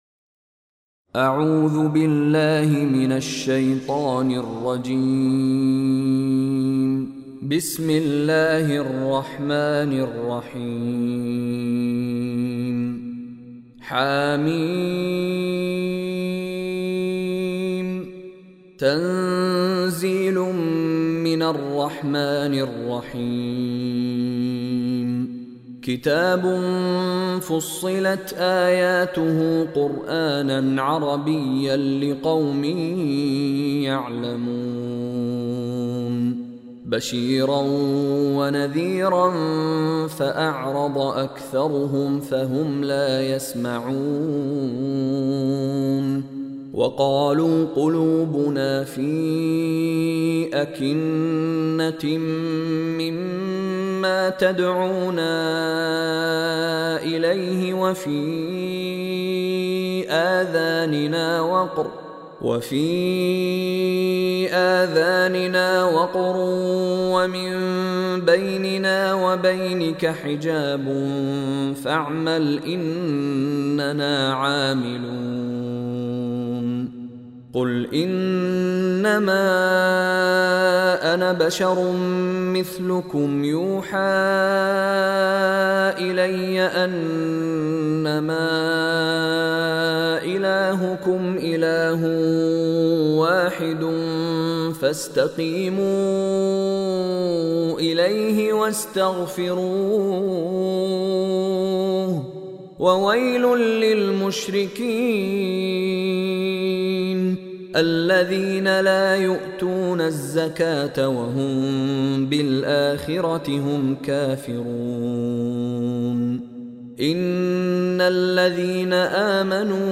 Surah Fussilat Recitation by Sheikh Mishary Rashid
Listen online and download recitation of Surah Fussilat in the beautiful voice of Sheikh Mishary Rashid Alafasy.